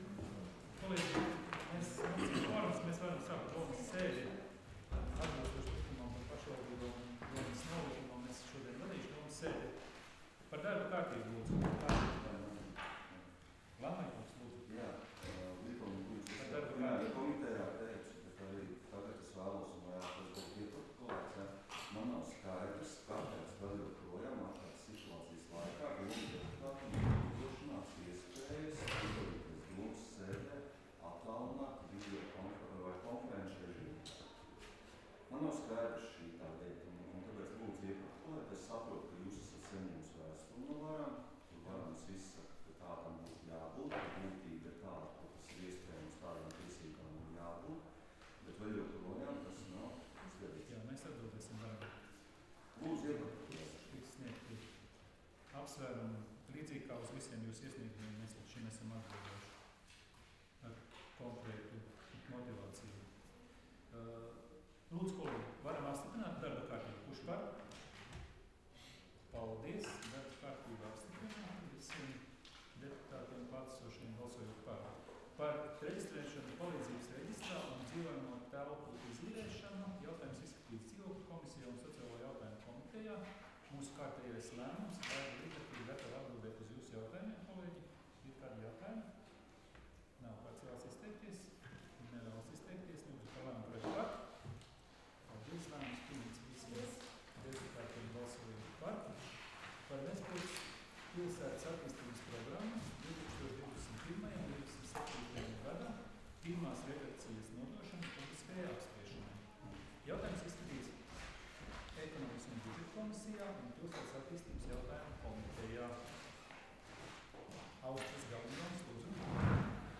Domes ārkārtas sēdes 27.07.2021. audioieraksts